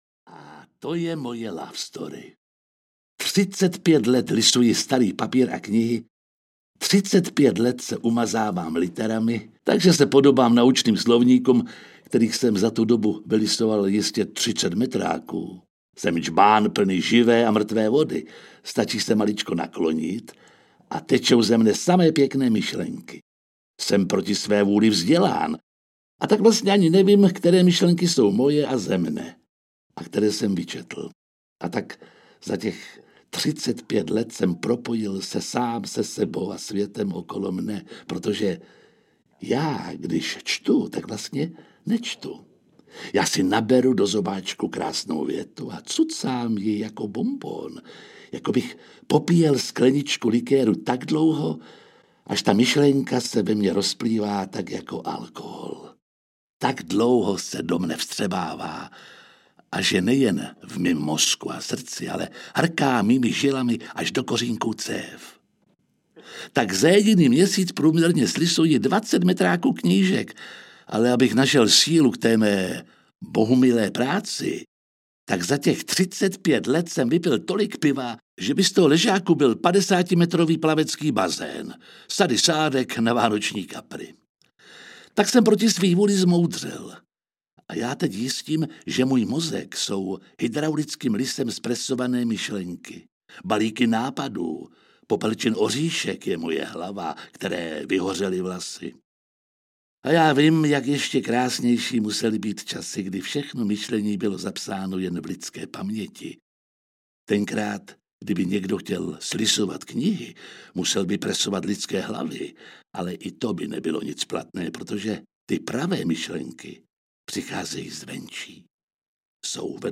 Ukázka z knihy
• InterpretJiří Lábus